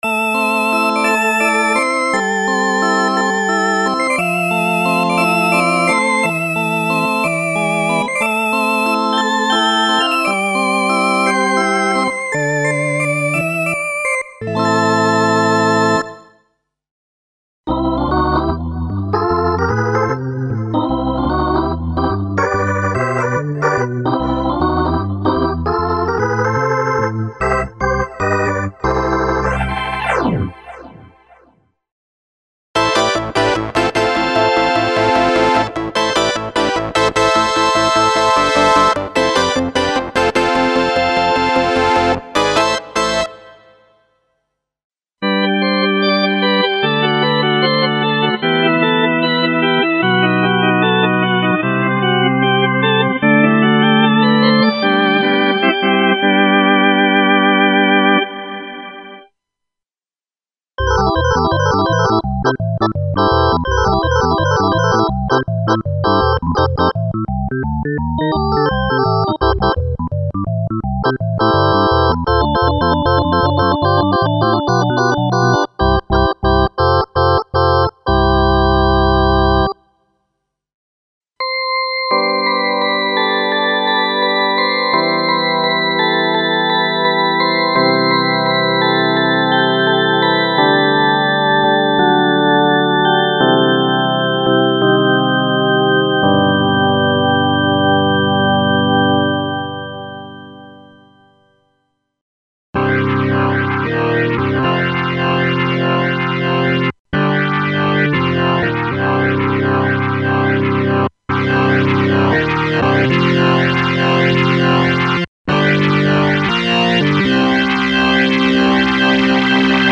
Ultimate collection of electric organ emulations based on Kurzweil PC3K´s internal ROM samples (organ, organ drawbars and organ partials) and KB3 Mode.
This soundware collection contains the vintage electric organs including filtered, specially modulated and distorted organ sounds for various music styles.
Info: Some of electric organ sound programs contain the individual sound program layer of the key release emulation (controlled via MIDI 29 – onboard On/Off – switch) to increase the vintage authenticity of the final sound.